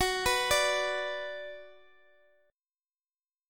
F#sus4#5 chord